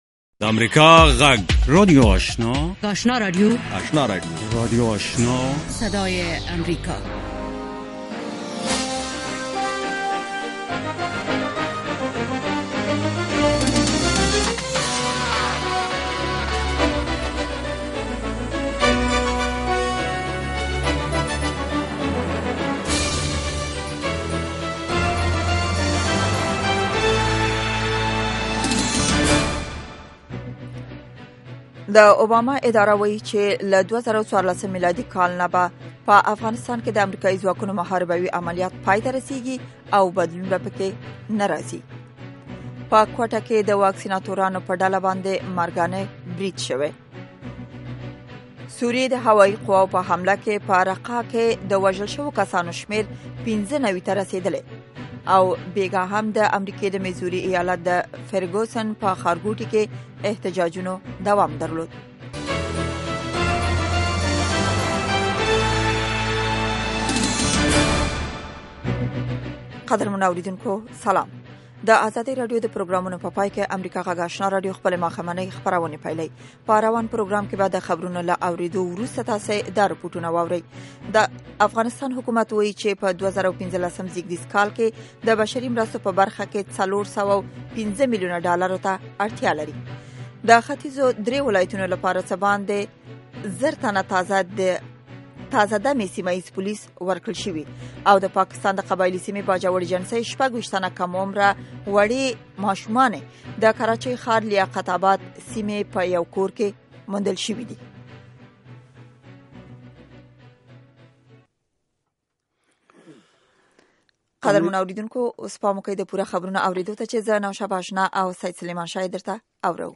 ماښامنۍ خبري خپرونه
د اوریدونکو پوښتنو ته ځوابونه ویل کیږي. ددغه پروگرام په لومړیو ١٠ دقیقو کې د افغانستان او نړۍ وروستي خبرونه اورئ.